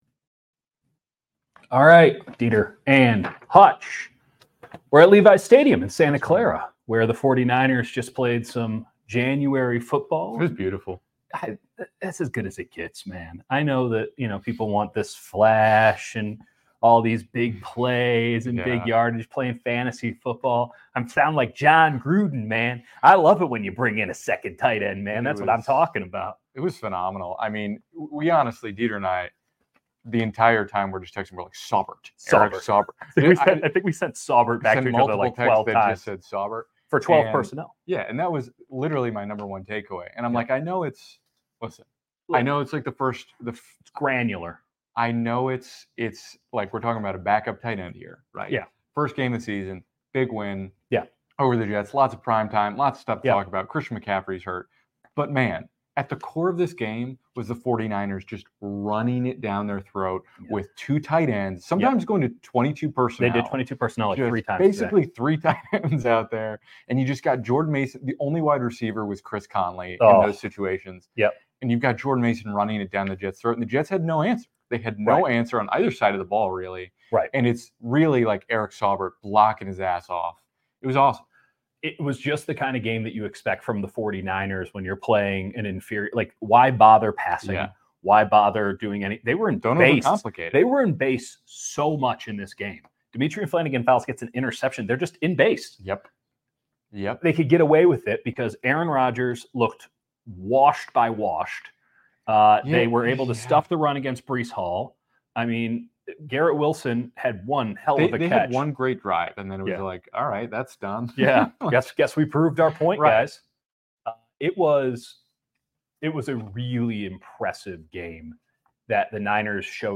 49ers-Jets Postgame LIVE from Levi’s Stadium | 49ers DISMANTLE the Jets